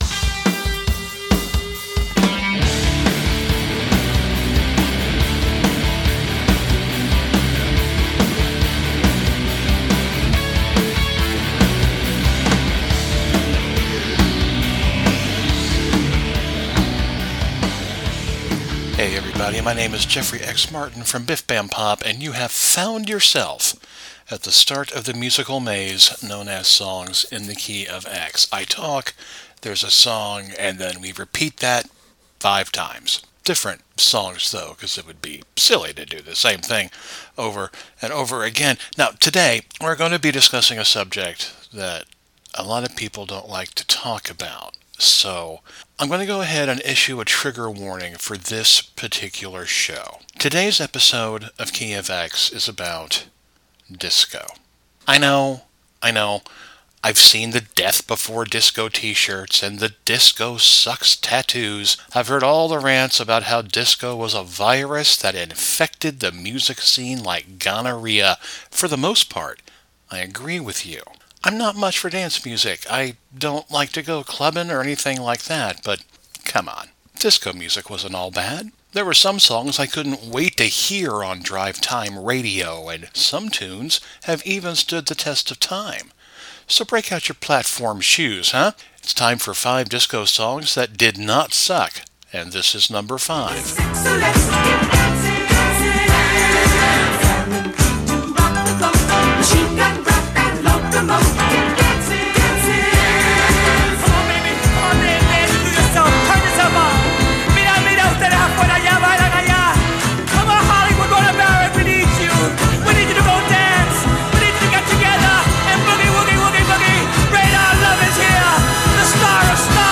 Regardless of your reaction, listen to the tiniest radio show on the internet.